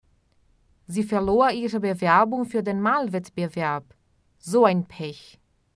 Logo abaixo, você vai encontrar várias frases com áudios de exemplo para você treinar o uso correto desta frase e sua pronúncia.